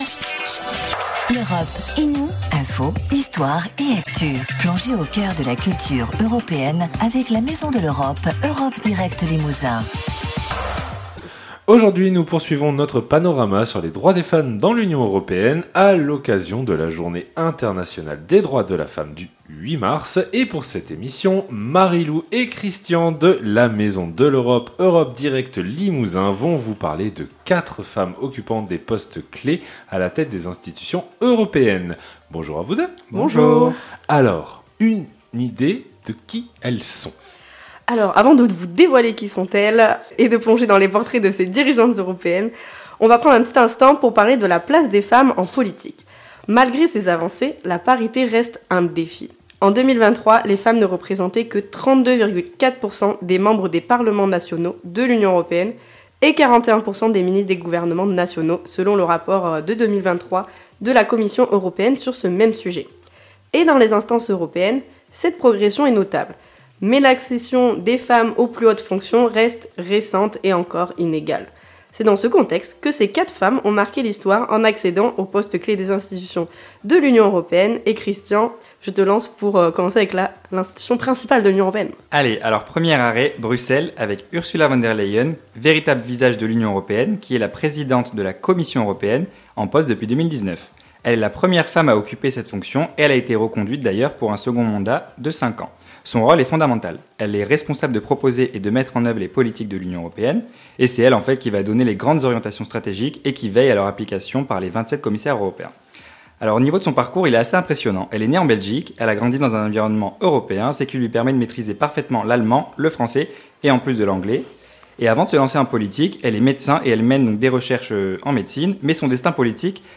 dirigeantes , égalité femmes hommes , femmes politiques , institutions européennes , journée internationale , radio